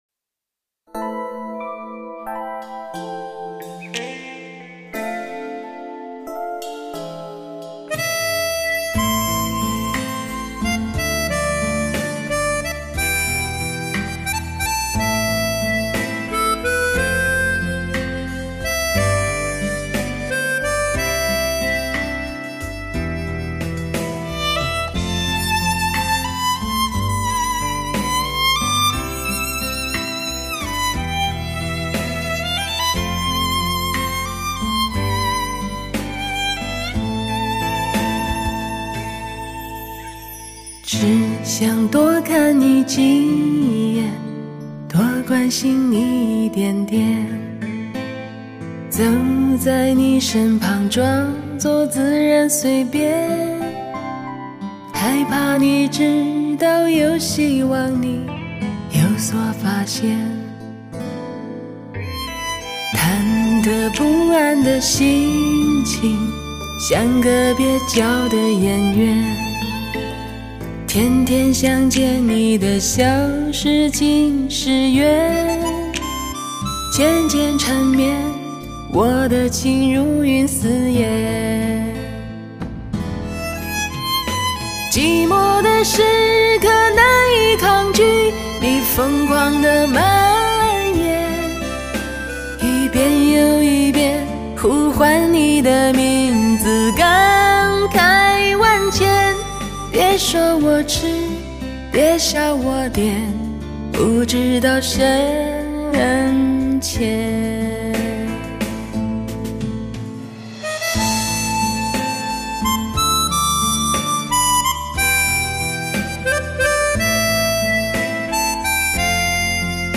完美环绕+最新科技DTS-ES6.1CD，还原最真实的HI-FI声场效果营造最逼真的现场氛围。
浅吟低唱  紧扣心弦